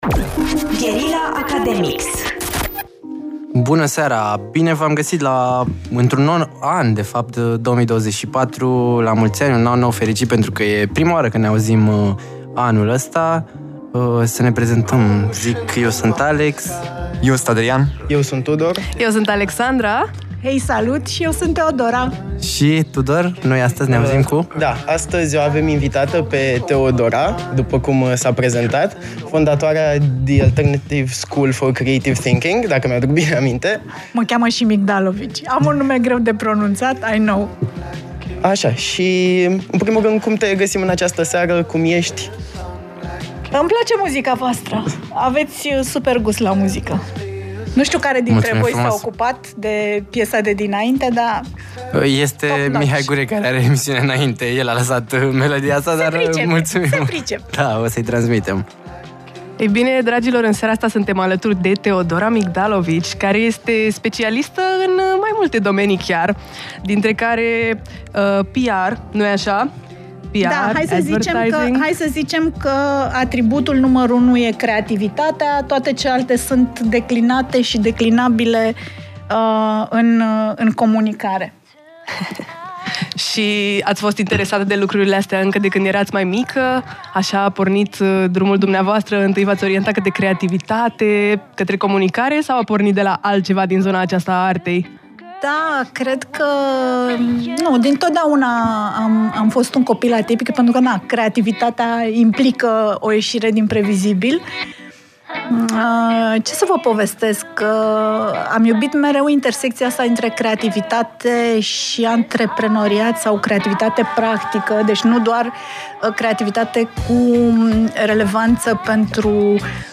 Academics este emisiunea de la Radio Guerrilla ce îi are drept gazde pe elevii din primele sezoane Uman Real, care acum au crescut și au devenit studenți.